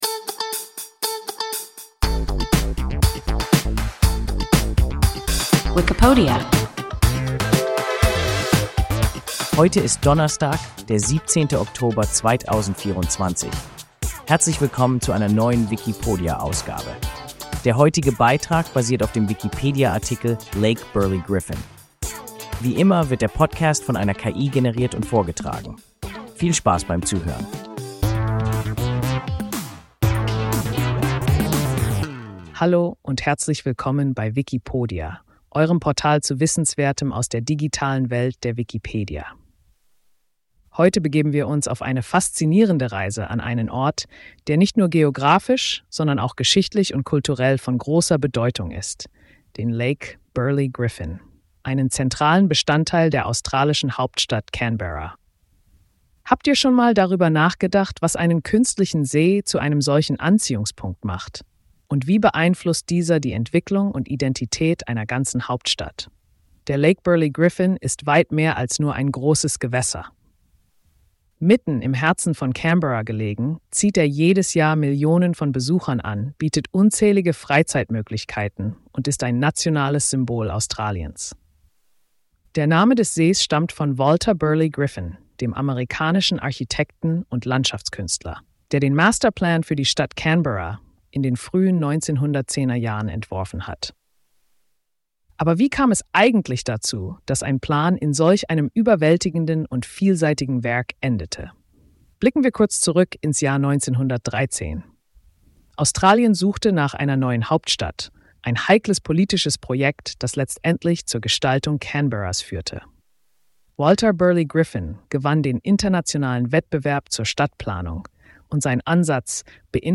Lake Burley Griffin – WIKIPODIA – ein KI Podcast